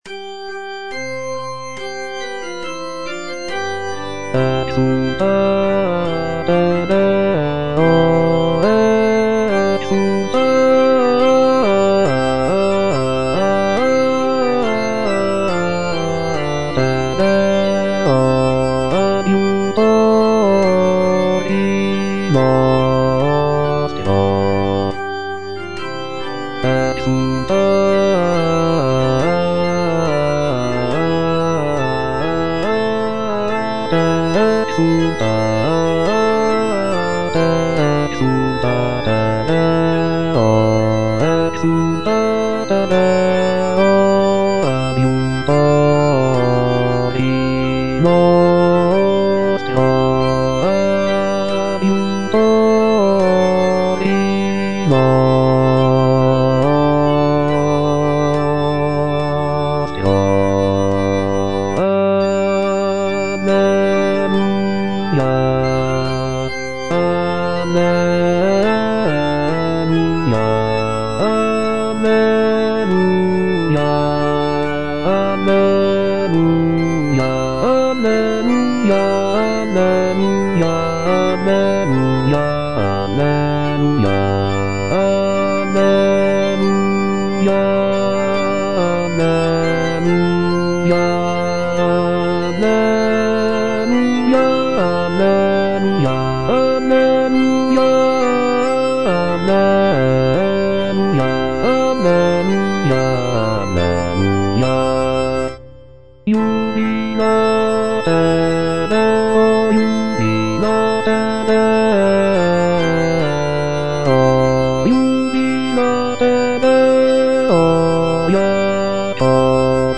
A.SCARLATTI - EXULTATE DEO (EDITION 2) Bass (Voice with metronome) Ads stop: auto-stop
"Exultate Deo (edition 2)" by A. Scarlatti is a sacred choral work that showcases the composer's mastery of the Baroque style. The piece is known for its uplifting and jubilant tone, with intricate counterpoint and rich harmonies.